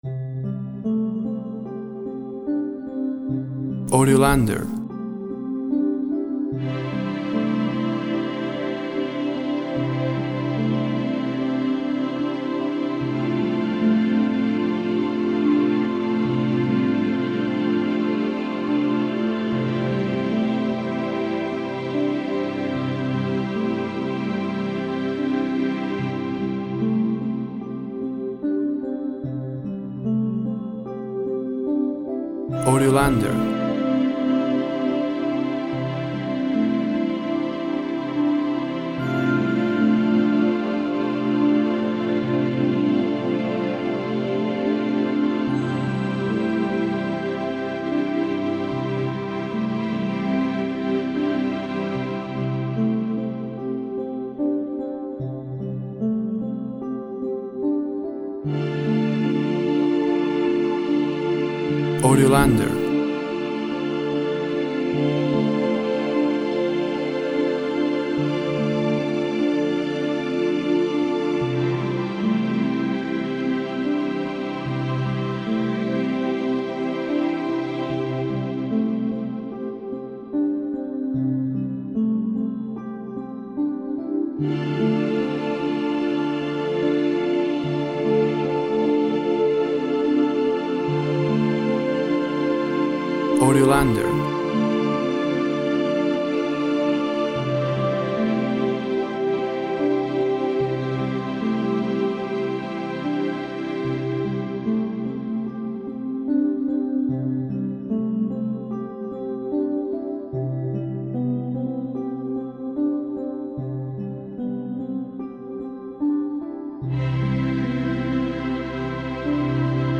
Synth harp and strings create a gentle, floating atmosphere.
Tempo (BPM) 74